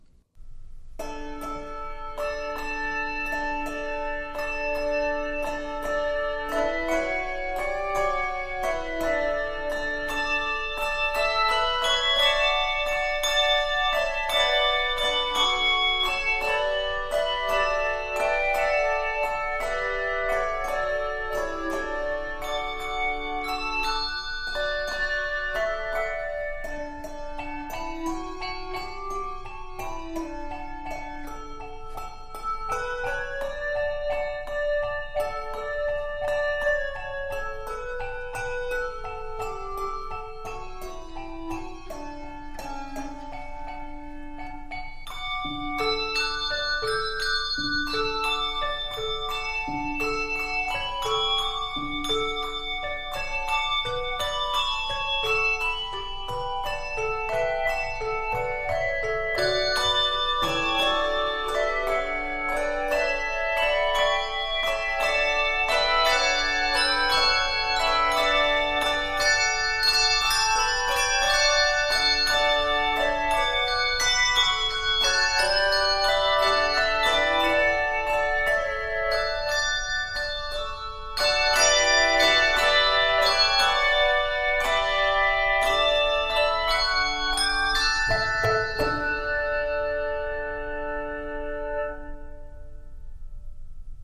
Key of D Major.
Octaves: 3